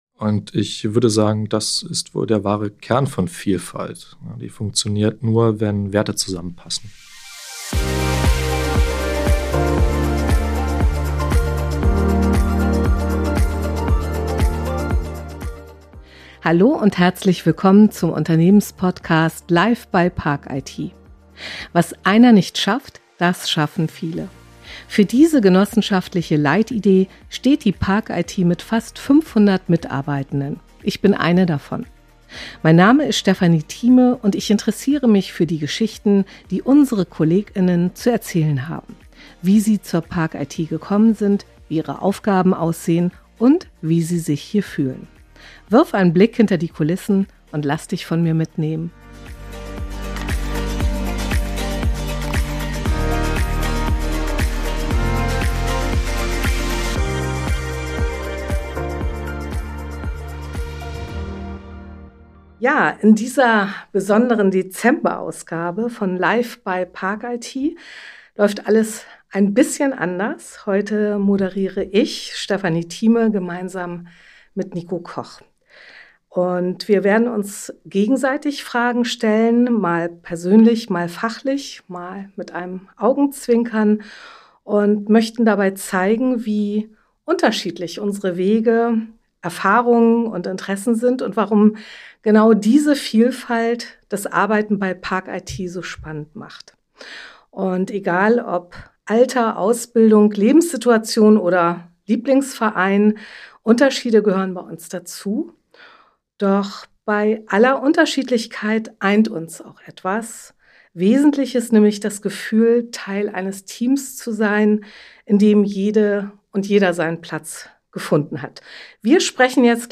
Ein ehrlicher Austausch über Karriere, Lebensphasen, Werte und kleine Alltagsfragen, der zeigt: Bei parcIT finden Unterschiedlichkeit und Zusammenhalt auf besondere Weise zusammen.